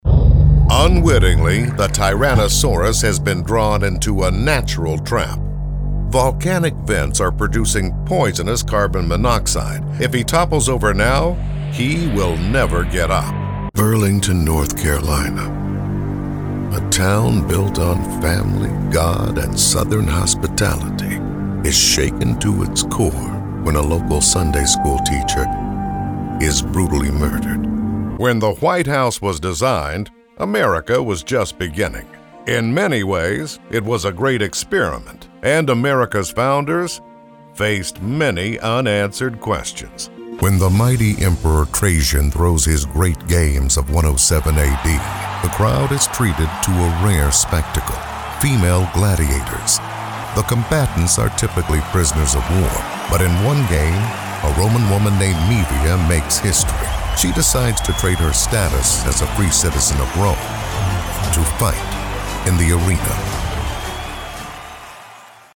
Male
Adult (30-50), Older Sound (50+)
Believable, real, guy-next-door, gravitas, voice of God, friendly, quirky, serious, dramatic, funny, whimsical, magical, relatable, honest, sophisticated, sexy, ardent, warm, fuzzy, clever, professor, Sam Elliott, Berry White, extreme, normal, bizarre, business, narrator, Nat Geo narrator, Mike Rowe, evil, scary, mysterious, blue collar, forceful.
Narration